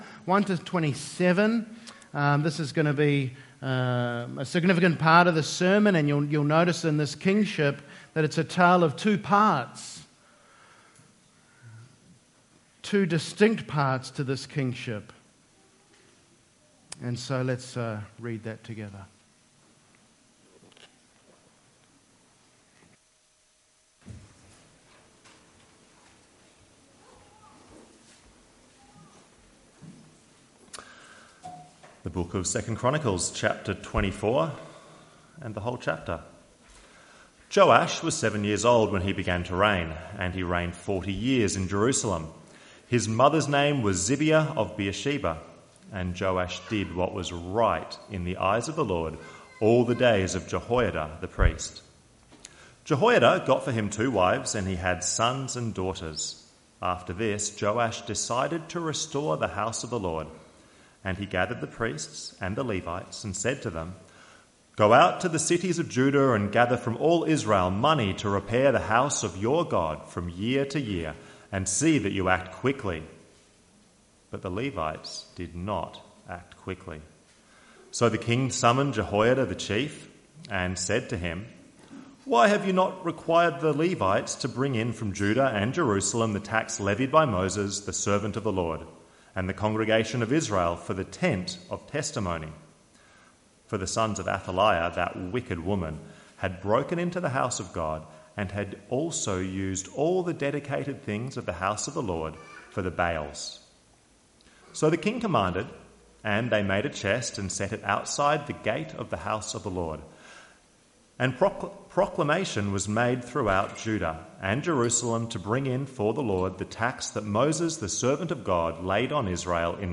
‹ Close Log In using Email Mar 03, 2024 Prov. 13:20 – Wise Connections MP3 SUBSCRIBE on iTunes(Podcast) Notes Evening Service - 3rd March 2024 2 Chronicles 24:1-27, Proverbs 1:1-7, 2:1-22, 13:20